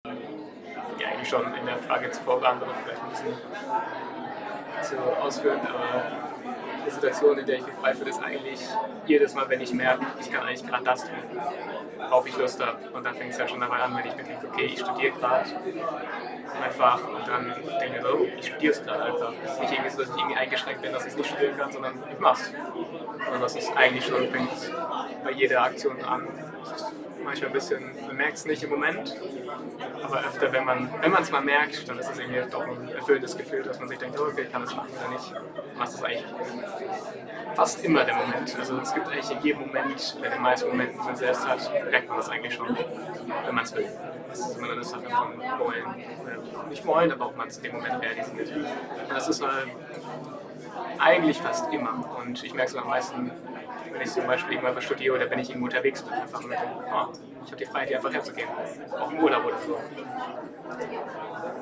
FALLING WALLS 2024 @ Falling Walls Science House, Berlin